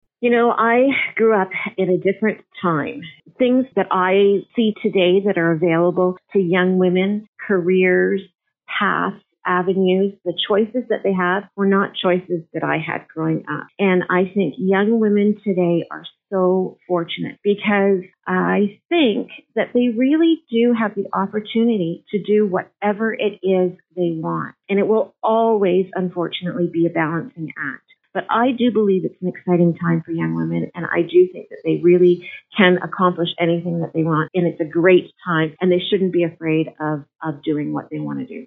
Here is the complete interview with Cathy Burghardt-Jesson.